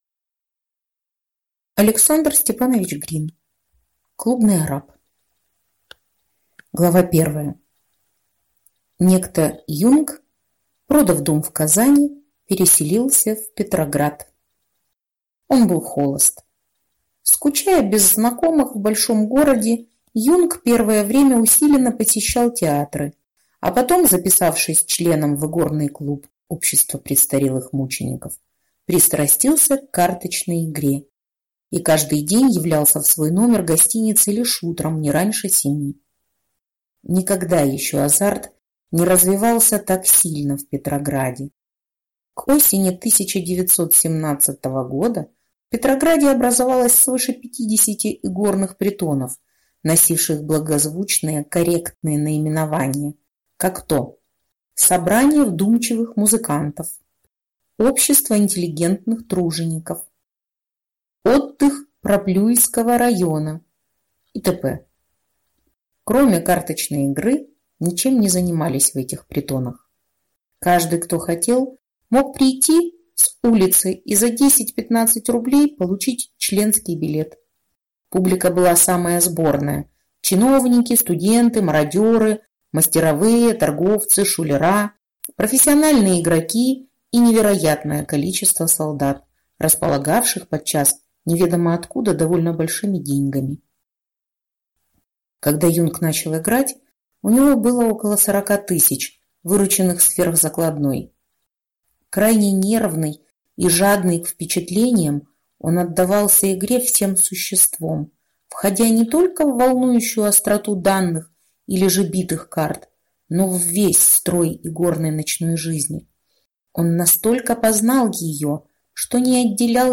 Аудиокнига Клубный арап | Библиотека аудиокниг